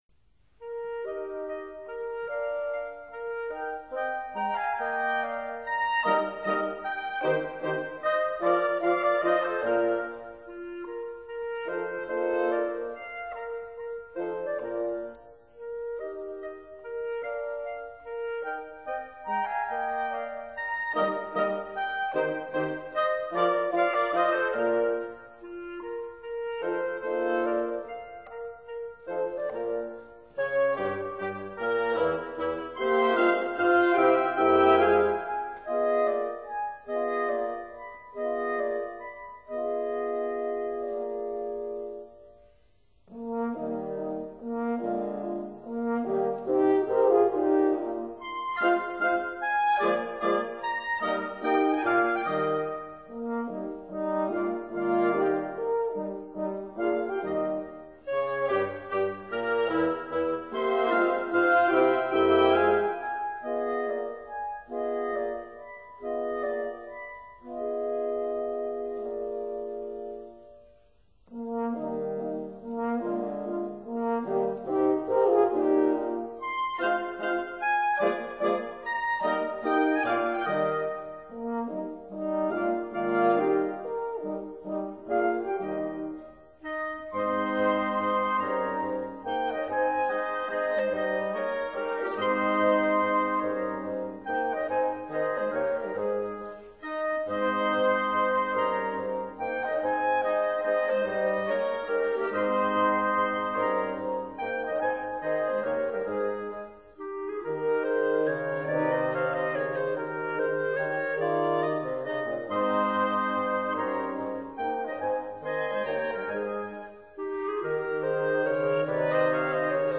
oboes
clarinets
bassoons
horns